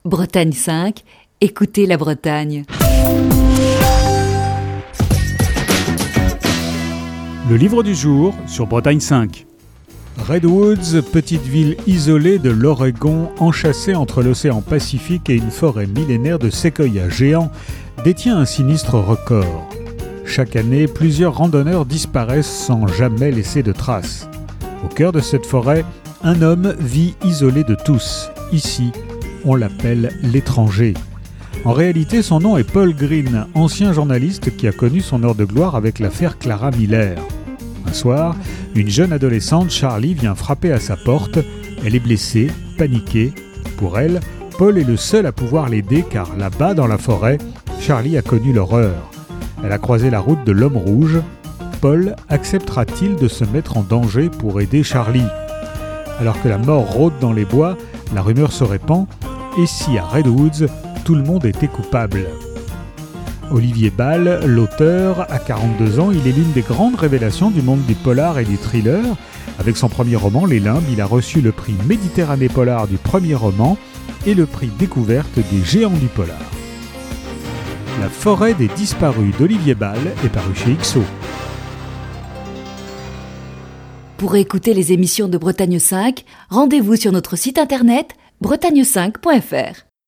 Chronique du 5 mai 2021.